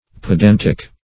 Pedantic \Pe*dan"tic\, Pedantical \Pe*dan"tic*al\, a.